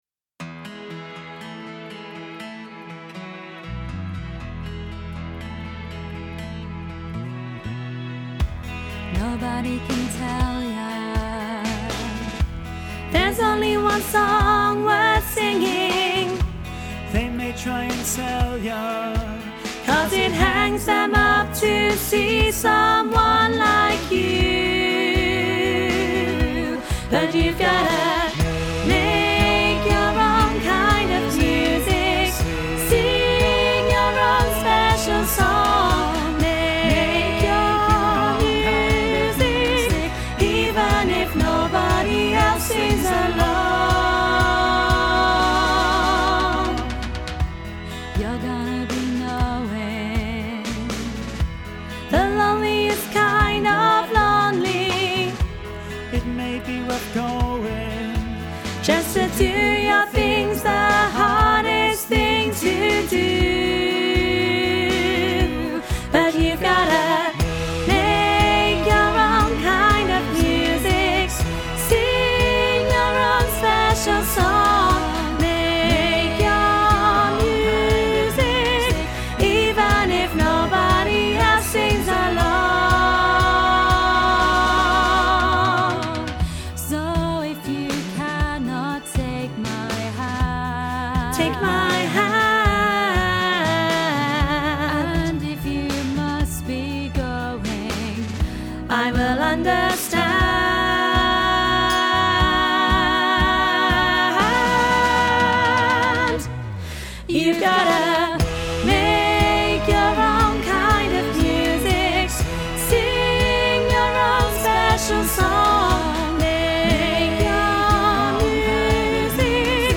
Training Tracks